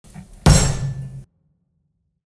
Table Slam Bouton sonore